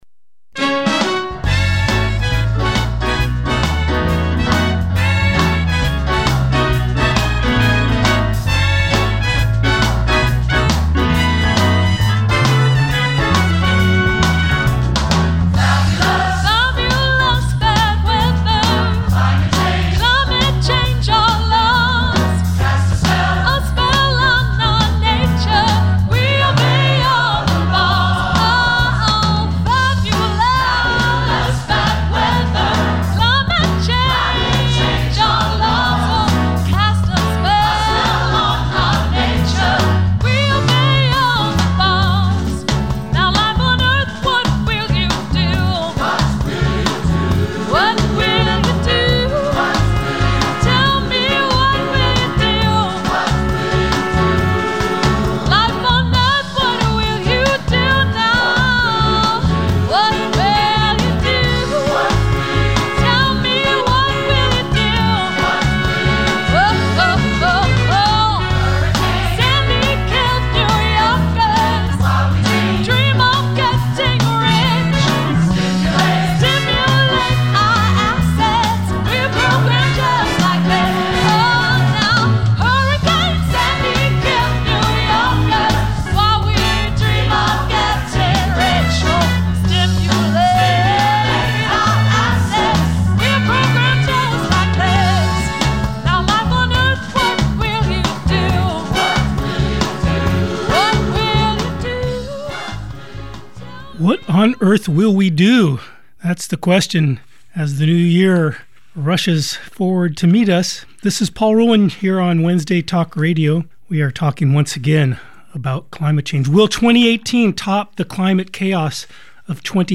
Opening song